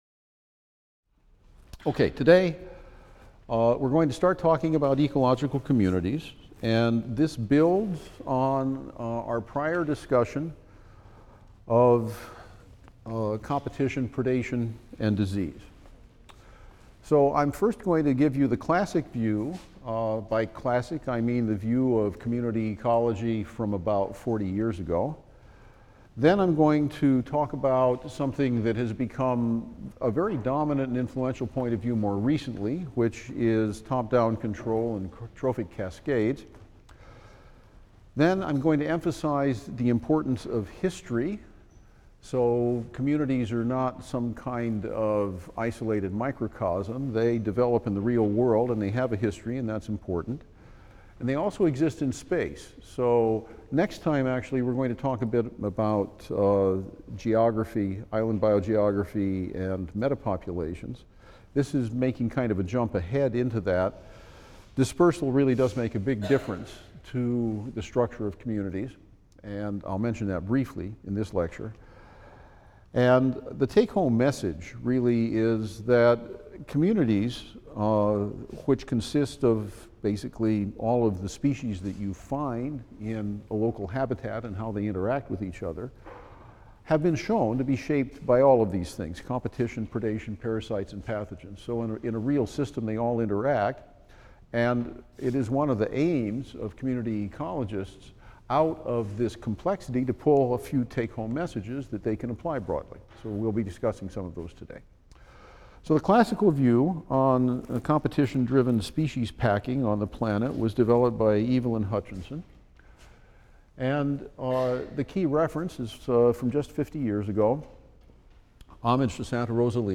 E&EB 122 - Lecture 28 - Ecological Communities | Open Yale Courses